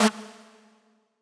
synth2_2.ogg